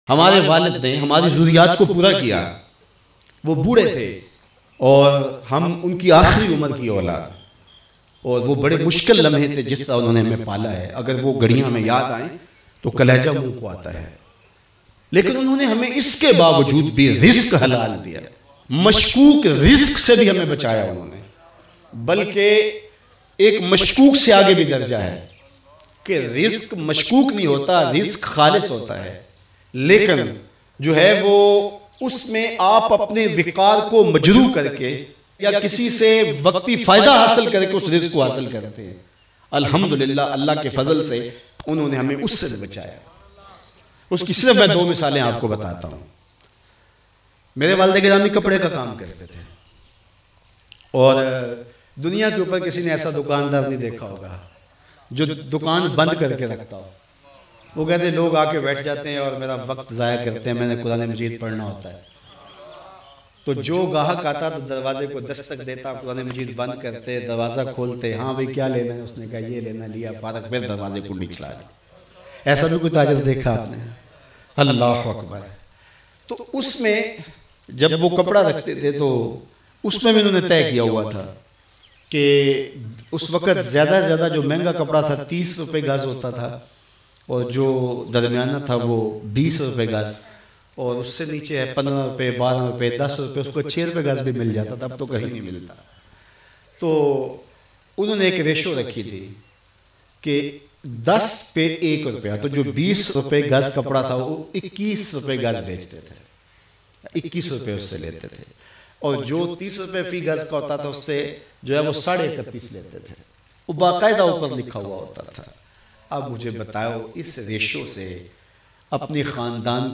Dukan Band Rakhne wala Anokha Tajir Bayan Mp3